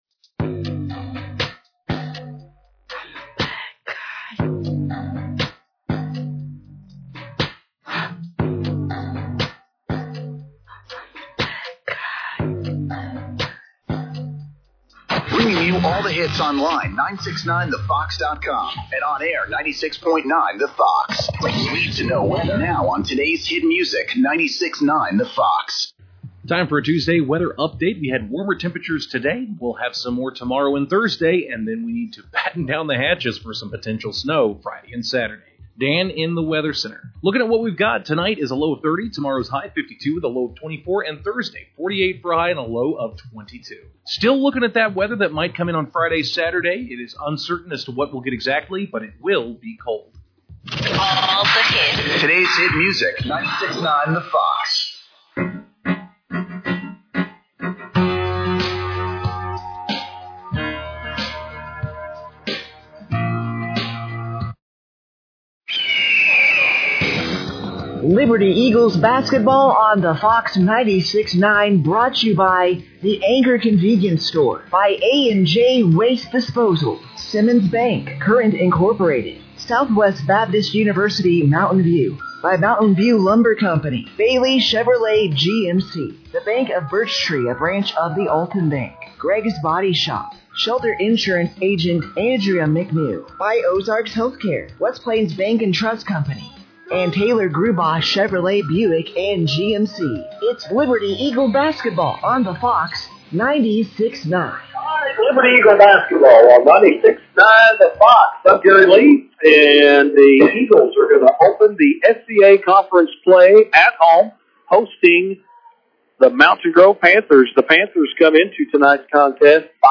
Liberty-Eagles-vs.-Mountain-Grove-Panthers-1-20-26.mp3